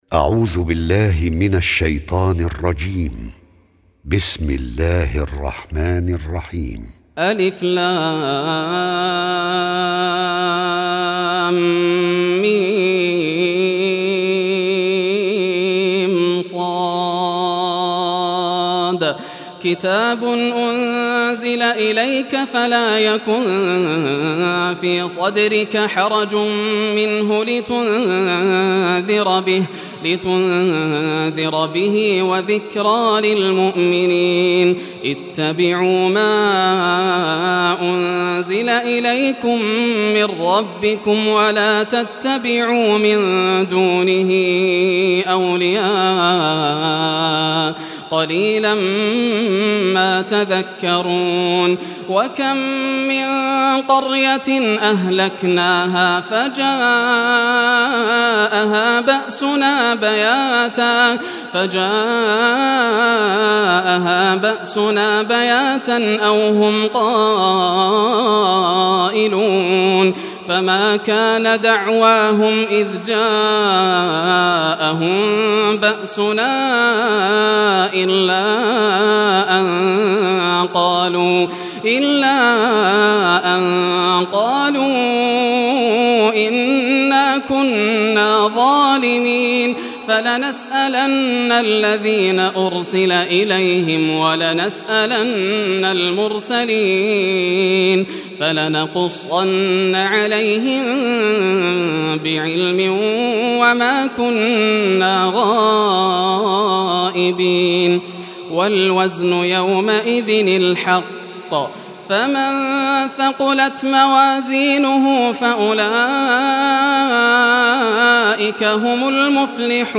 Surah Al-Araf Recitation by Yasser al Dosari
Surah Al-Araf, listen or play online mp3 tilawat / recitation in Arabic in the beautiful voice of Sheikh Yasser al Dosari.